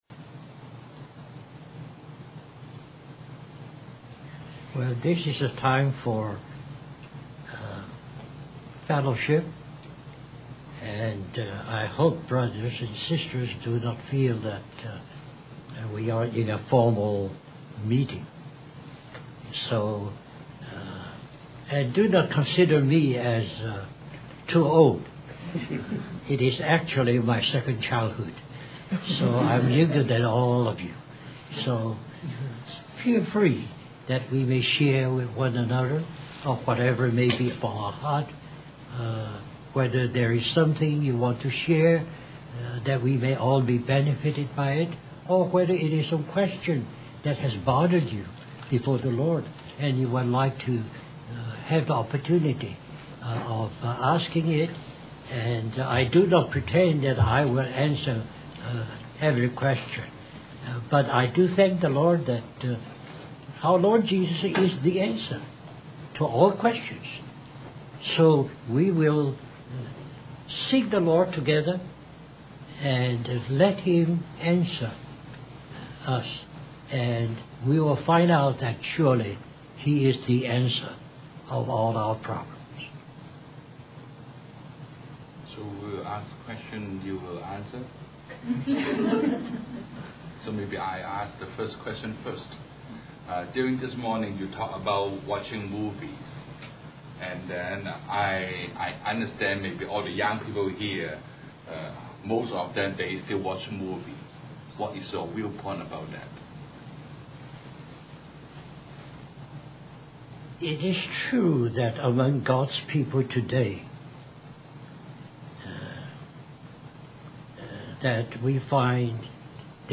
Questions And Answers With Young People On Serving The Lord
Vancouver, British Columbia, CA